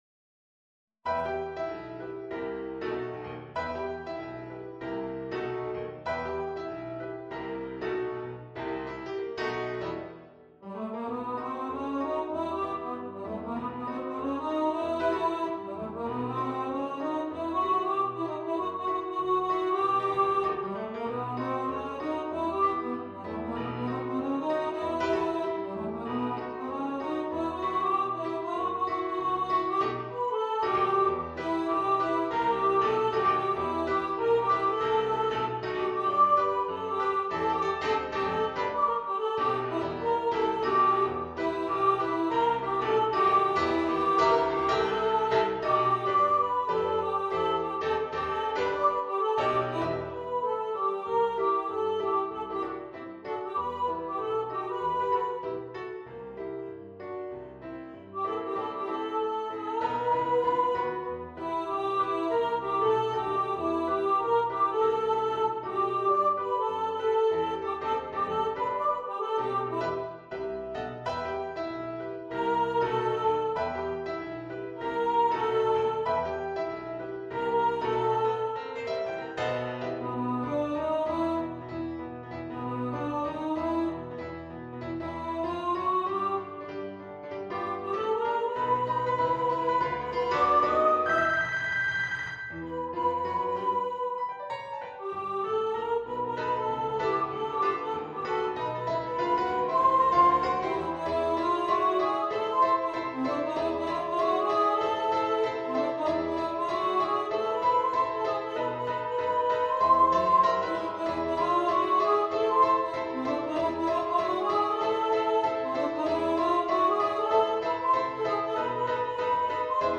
Friend-Like-Me-v2-Soprano.mp3